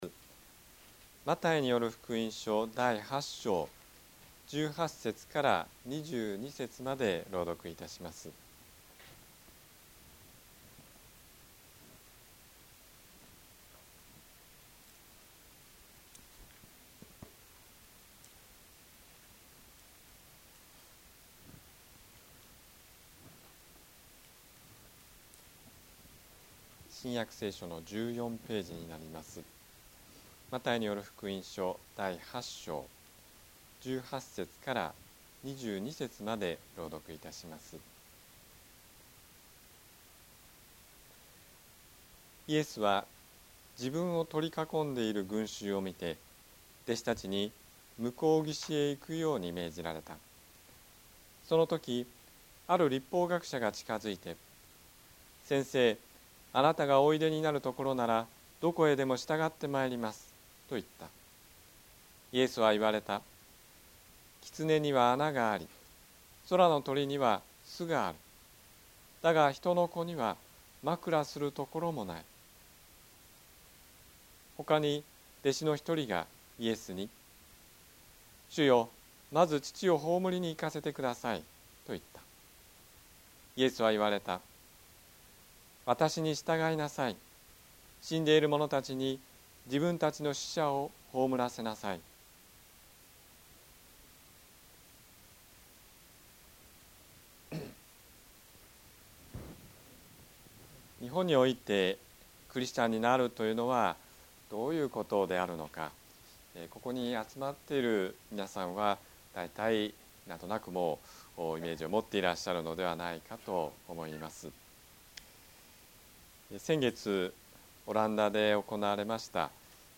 説教アーカイブ。
日曜 朝の礼拝